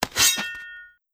Melee Weapon Draw 1.wav